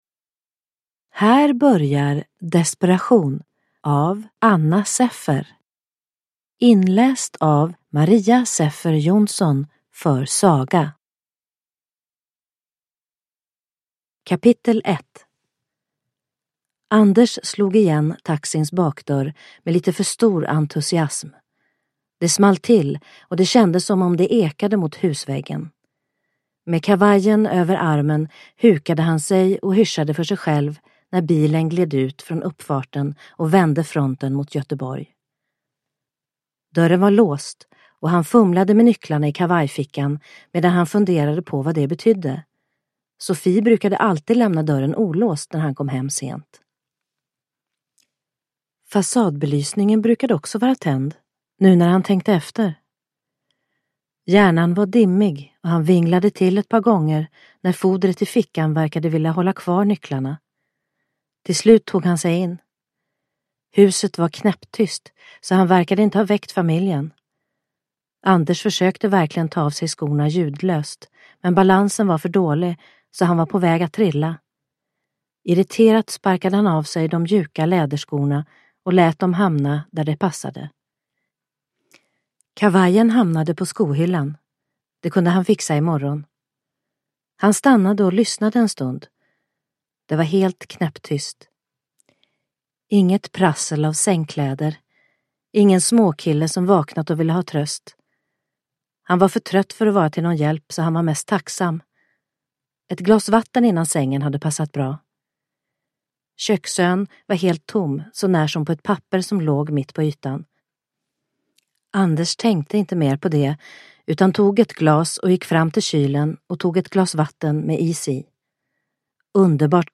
Desperation / Ljudbok